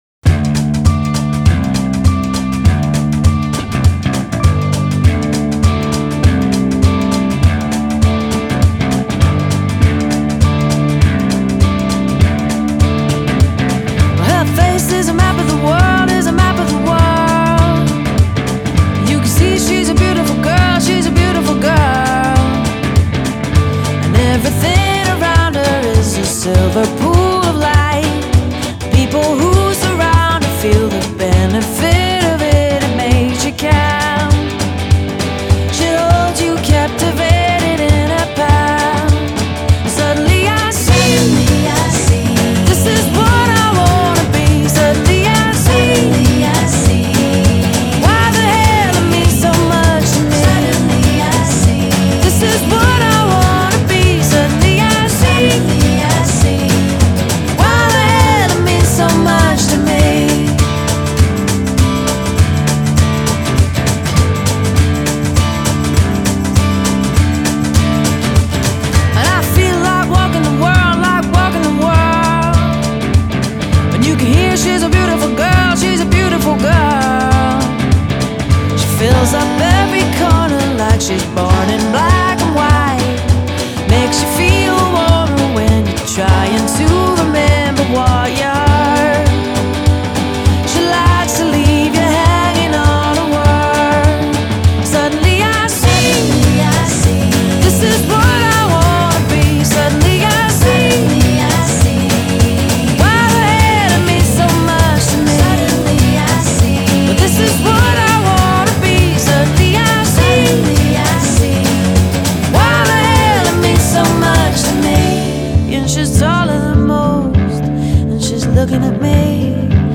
2004 Pop, Folk Pop